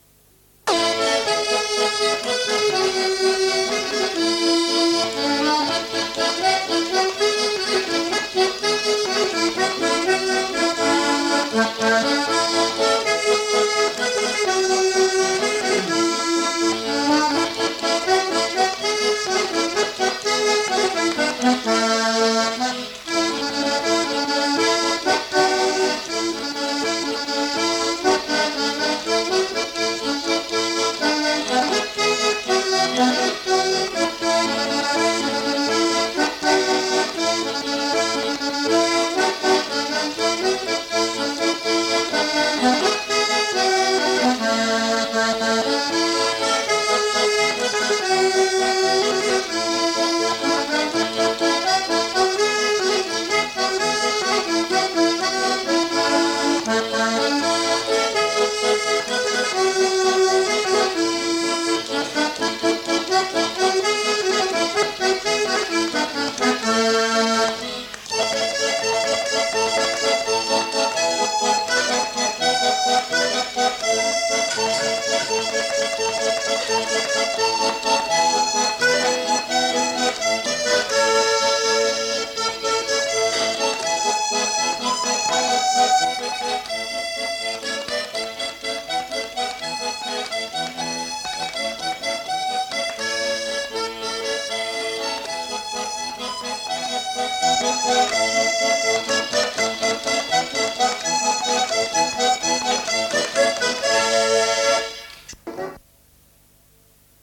Aire culturelle : Cabardès
Lieu : Mas-Cabardès
Genre : morceau instrumental
Instrument de musique : accordéon diatonique
Danse : valse
Notes consultables : Le joueur d'accordéon n'est pas identifié.